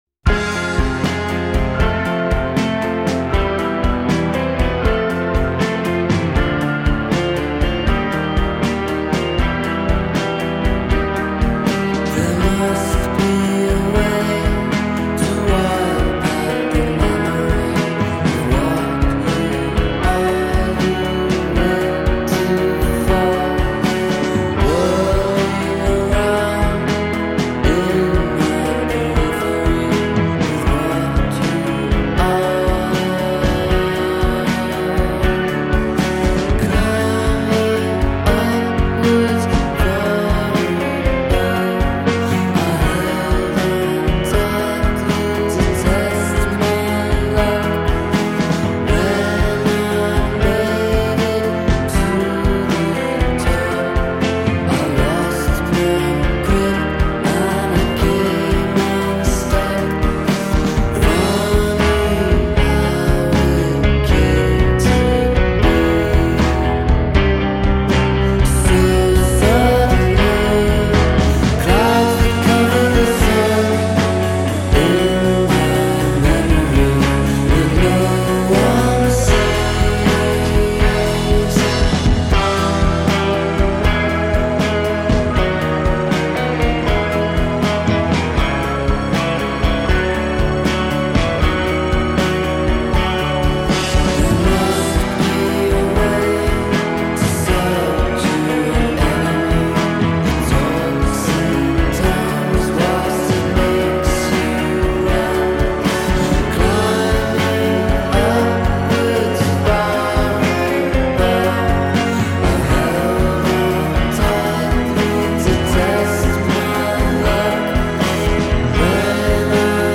Dayglo, Mind-Melt and tunes to get you to the world on time.